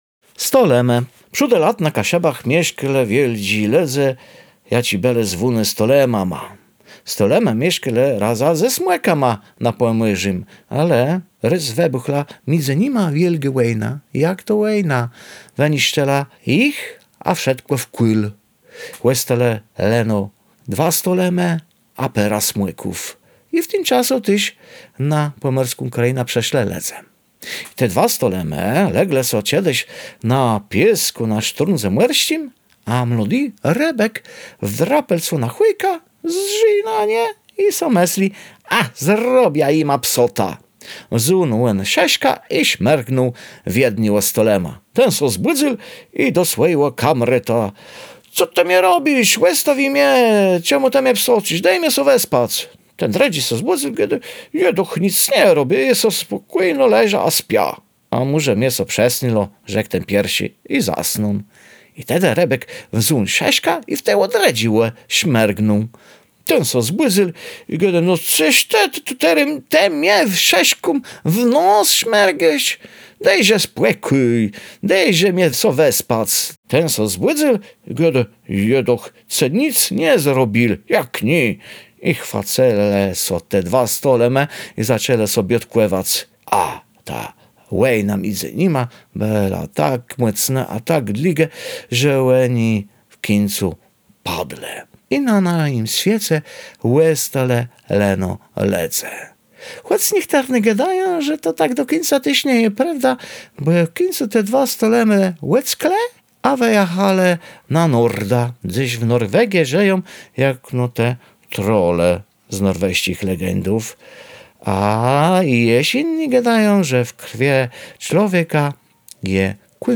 Bajka „O stolemach”.
18.STOLEM-GWARA.wav